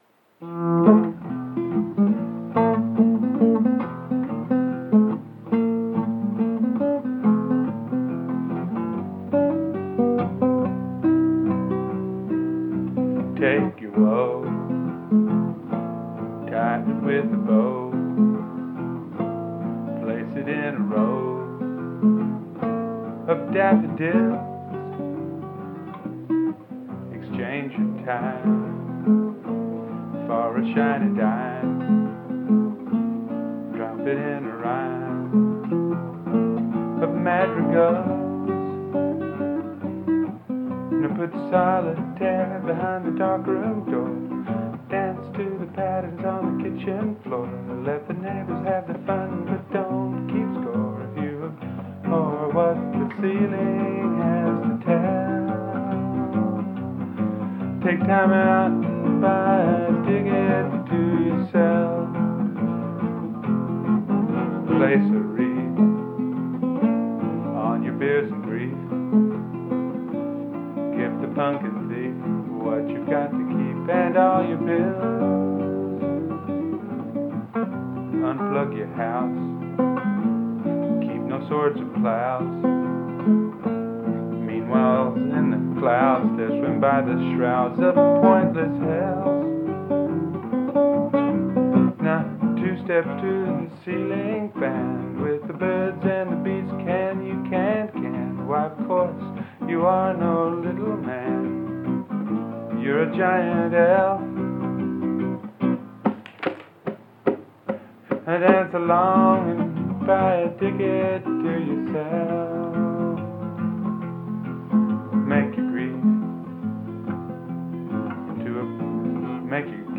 Song Name - acoustic
archival recordings
Guitar
usually too loud.